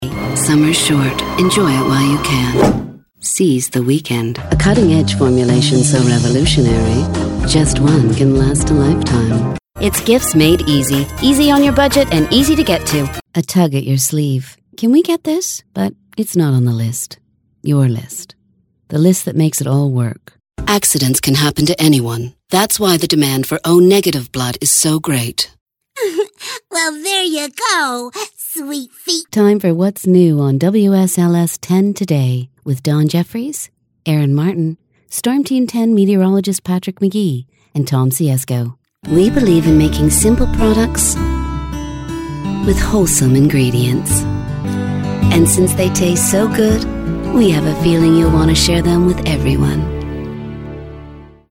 She can "do" sexy, soft sell effortlessly, but she can also do corporate, conversational and voices for animation.
Sprechprobe: Werbung (Muttersprache):